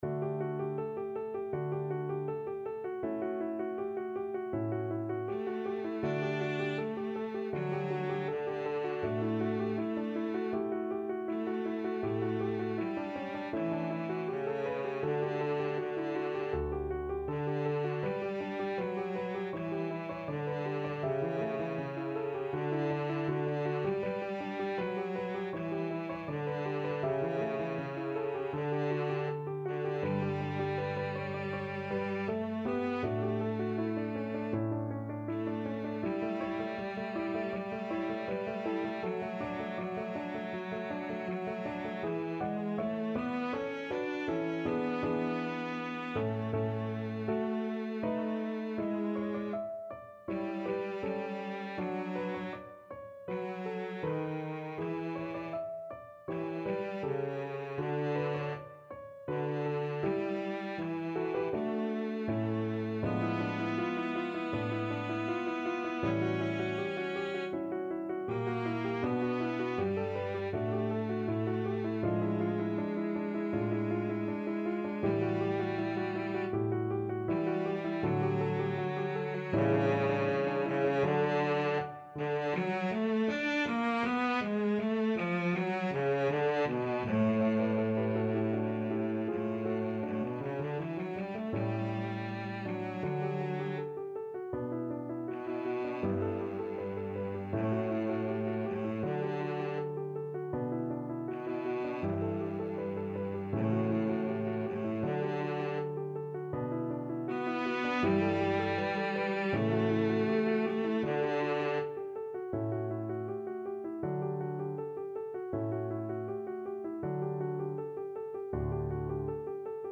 Cello 1Cello 2
Andante
Classical (View more Classical Cello Duet Music)